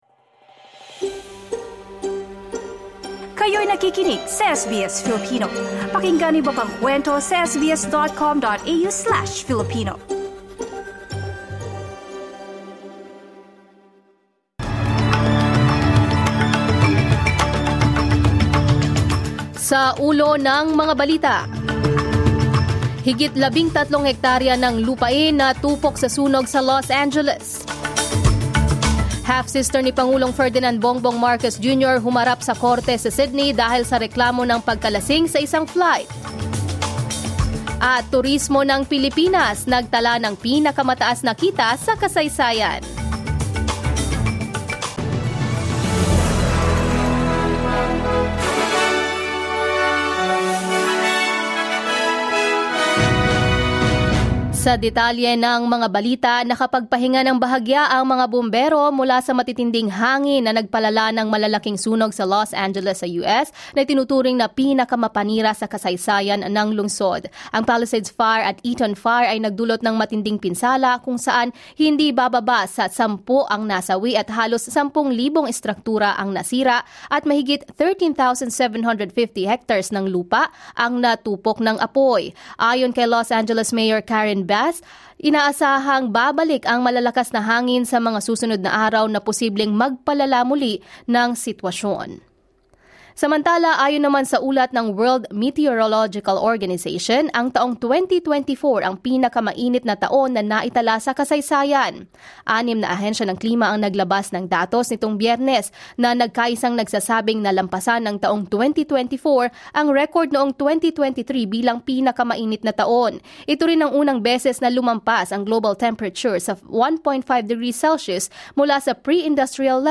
SBS News in Filipino, Saturday 11 January 2025